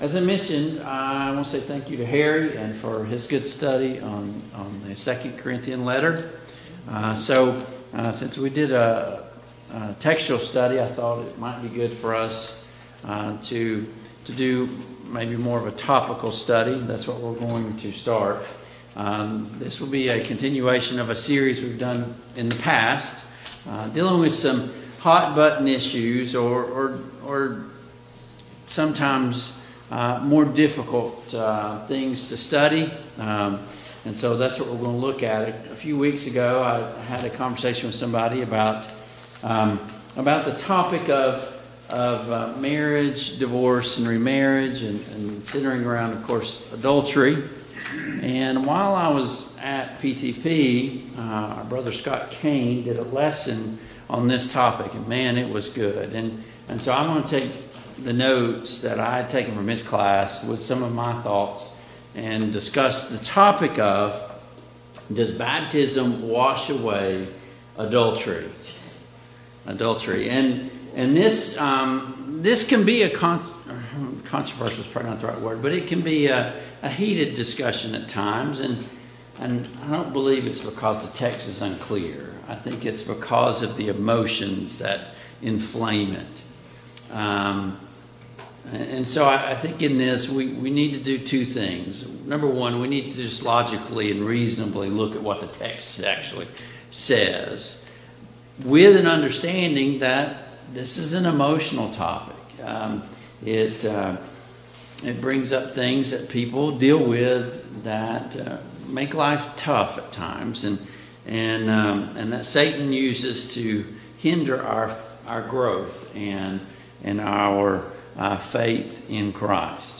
1 John 1:5-2:1 Service Type: Sunday Morning Bible Class Download Files Notes « 21.